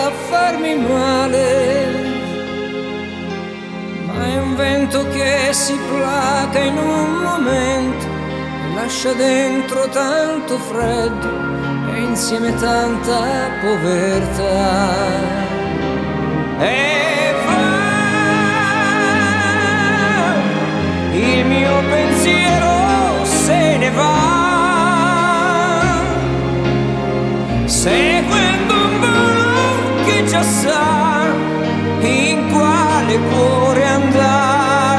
Pop Vocal
Жанр: Поп музыка